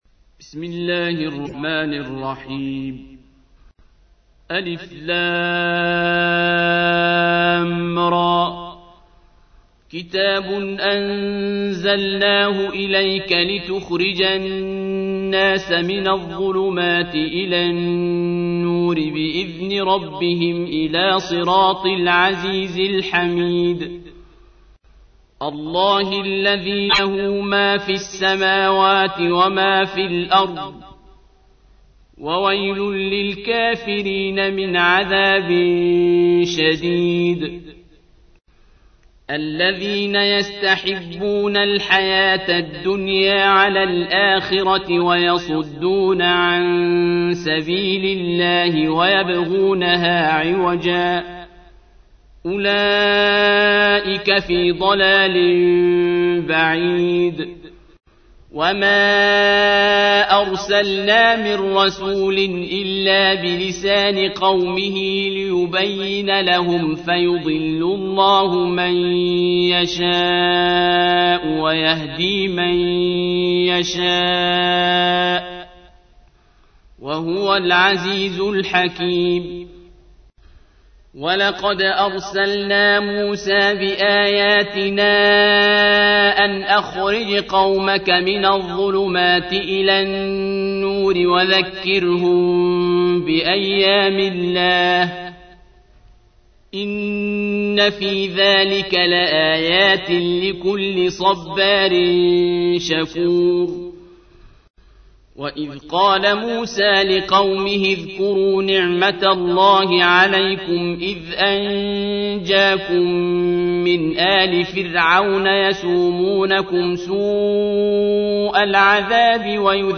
تحميل : 14. سورة إبراهيم / القارئ عبد الباسط عبد الصمد / القرآن الكريم / موقع يا حسين